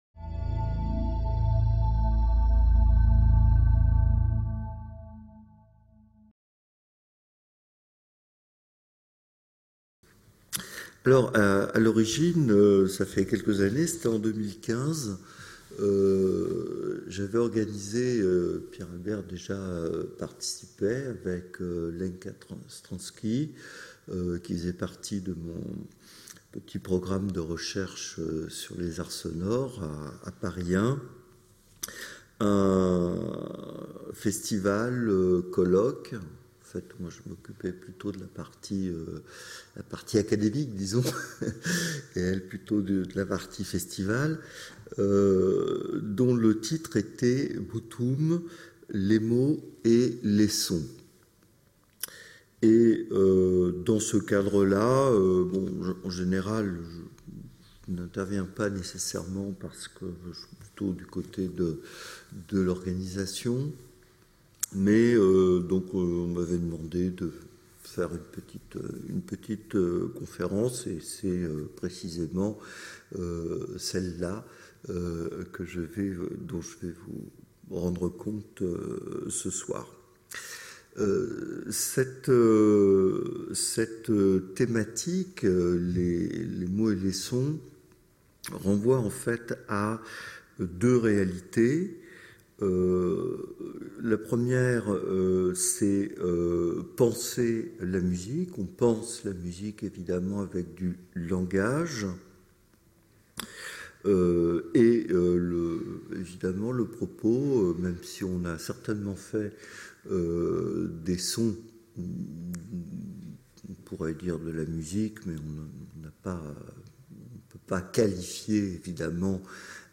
Conférence organisée à l’initiative du centre Iannis Xenakis.